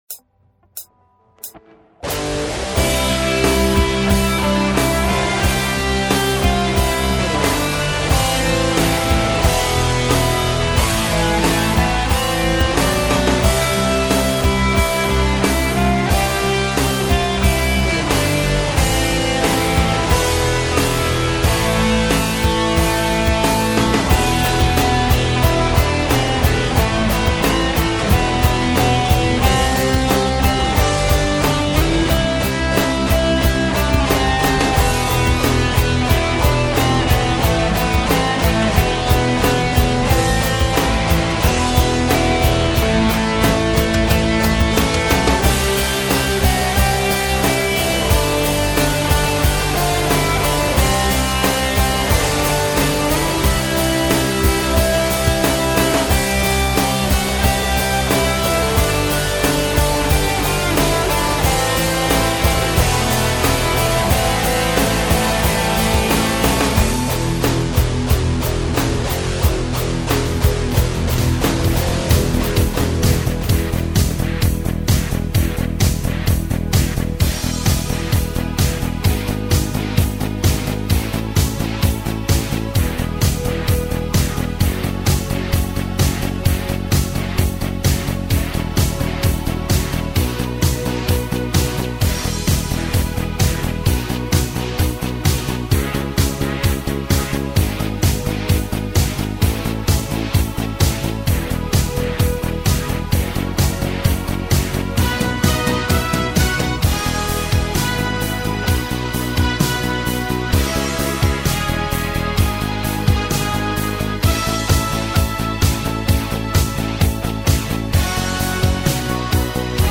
gimn_ukraini___rok_versija_2013_.mp3